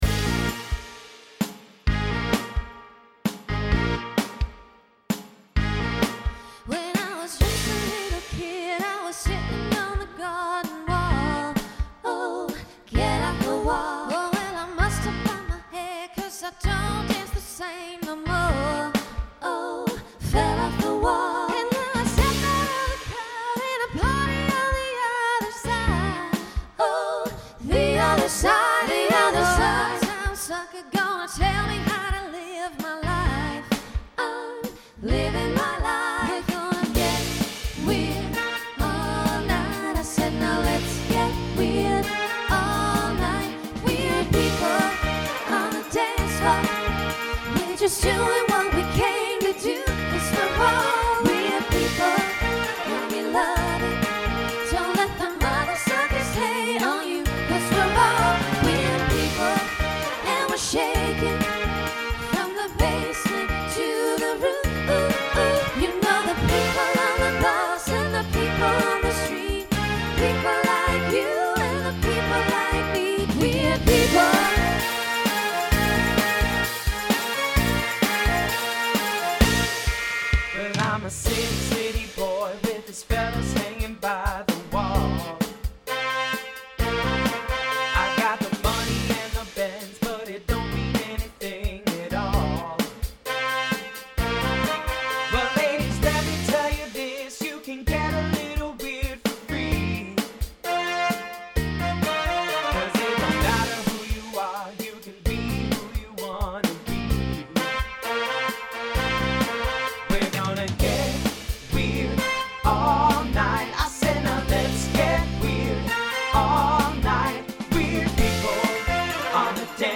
SSA/TTB
Voicing Mixed
Genre Pop/Dance